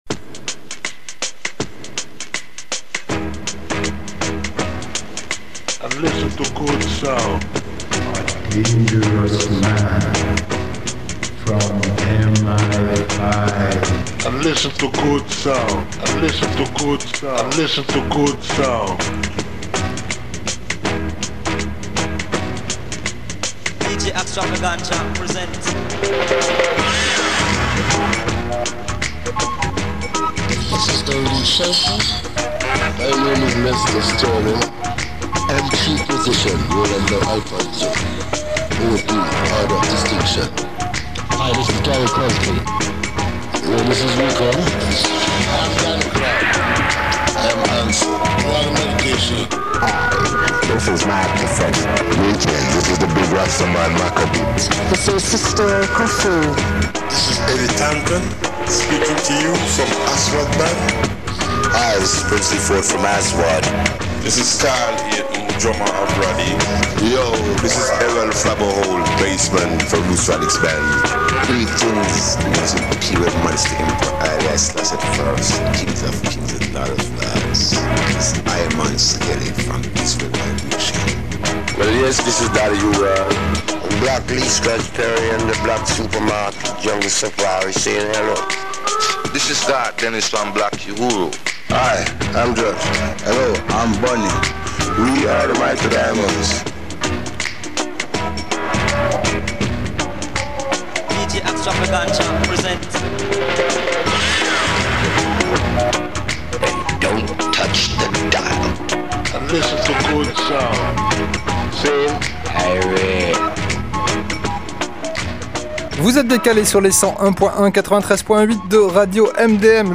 Mercredi 10 et samedi 13 avril 2024 (ska)